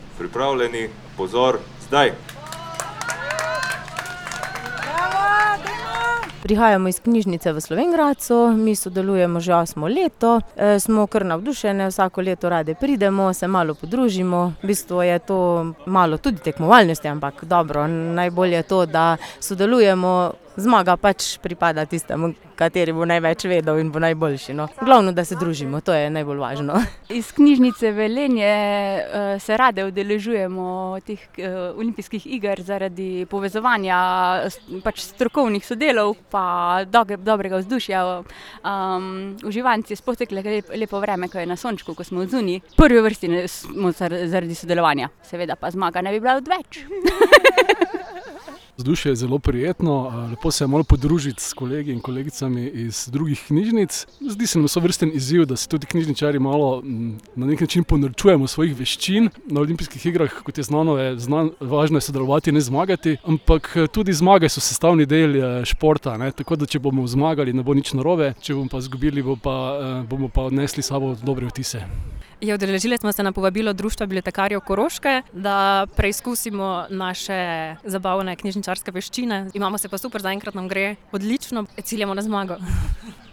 ANKETA.mp3